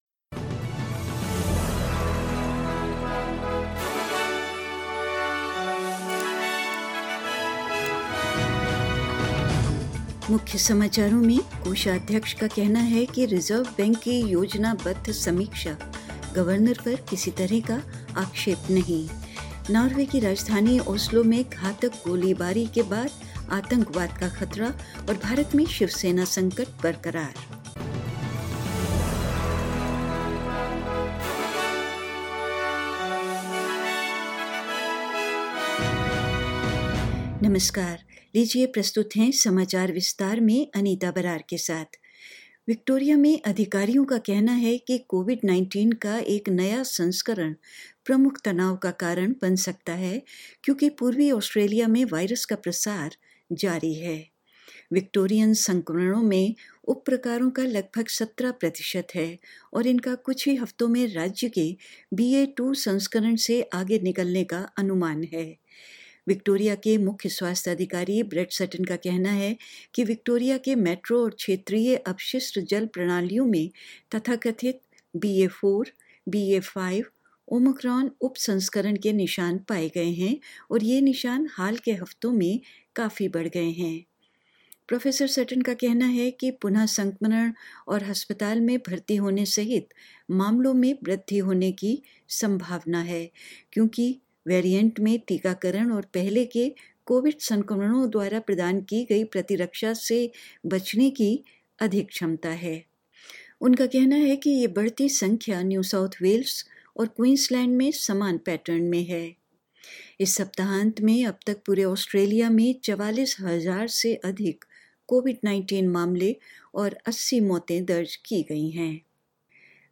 In this latest SBS Hindi bulletin: The treasurer says a planned review of the Reserve Bank not about attacking the governor; Norway raises its terrorism threat after a deadly shooting in the capital Oslo. In India, Shiv Sena crisis continues and more news.